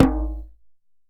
DJEM.HIT17.wav